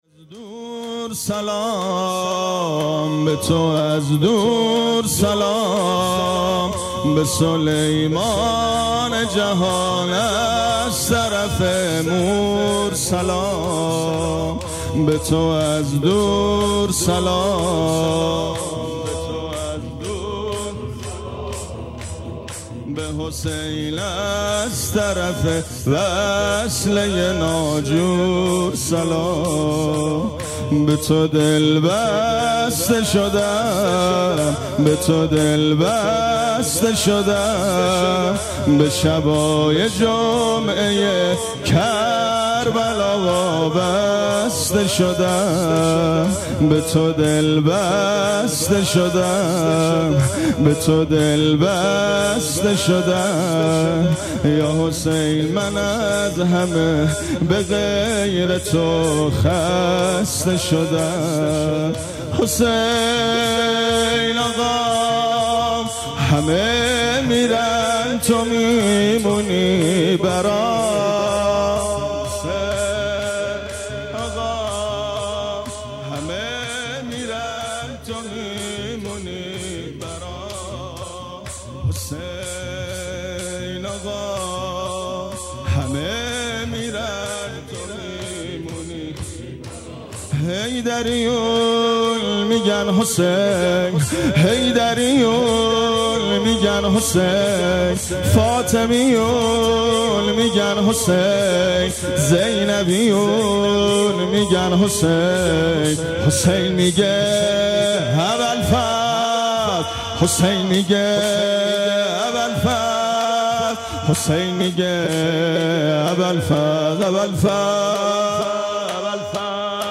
شب ۲۶ م ماه رمضان/ ۷ اردیبهشت ۴۰۱ شور مداحی ماه رمضان امام حسین علیه السلام اشتراک برای ارسال نظر وارد شوید و یا ثبت نام کنید .